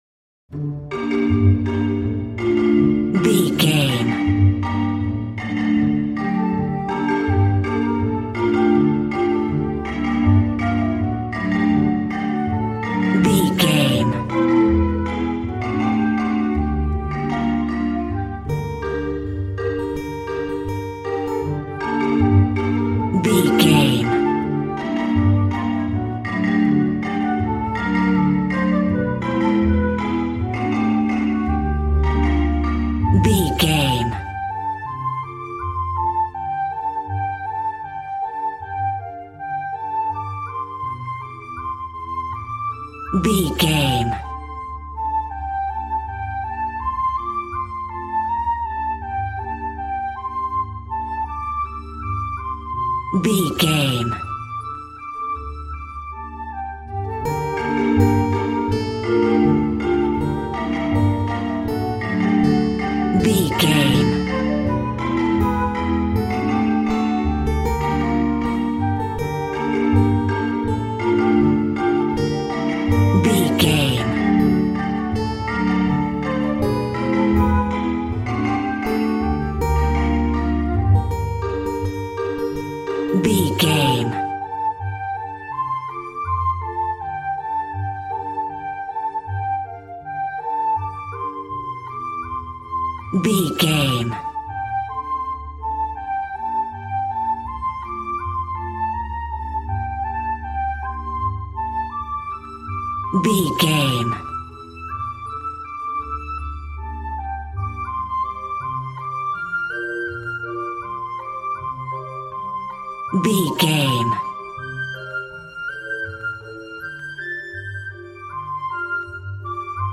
Ionian/Major
cheerful/happy
joyful
drums
acoustic guitar